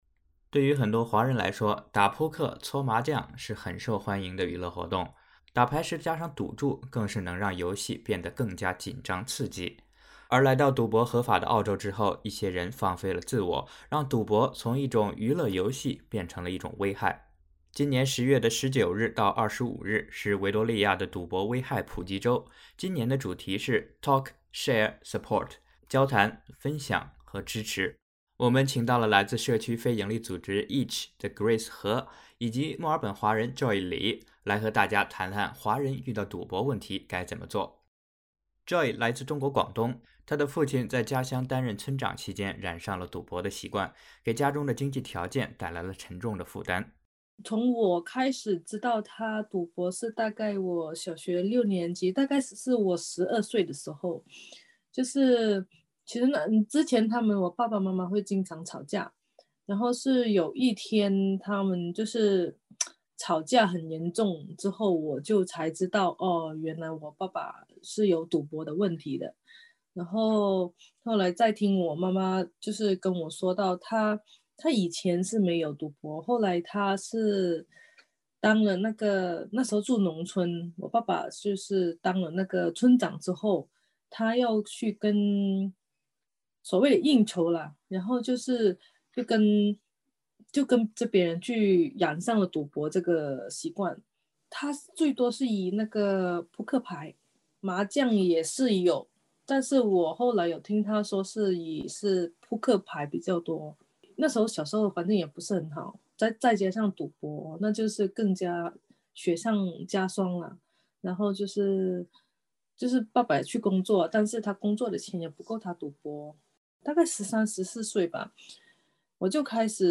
点击上方音频收听完整采访 关注更多澳洲新闻，请在Facebook上关注SBS Mandarin，或在微博上关注澳大利亚SBS广播公司。